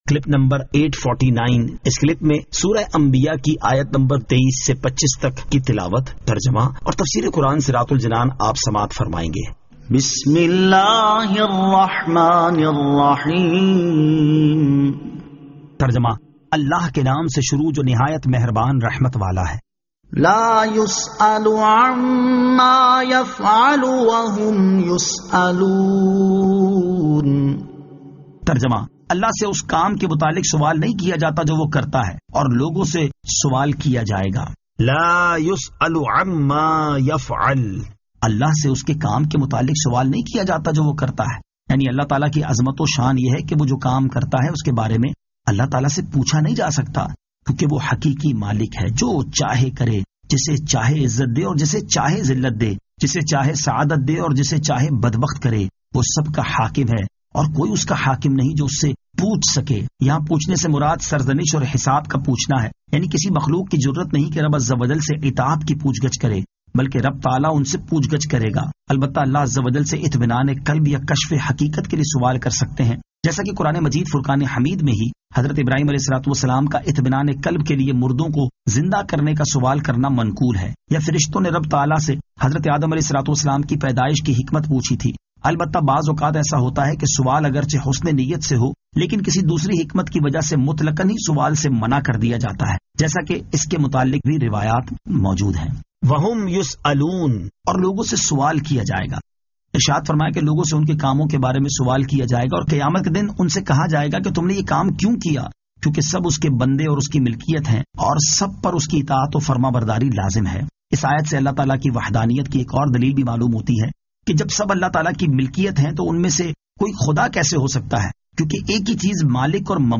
Surah Al-Anbiya 23 To 25 Tilawat , Tarjama , Tafseer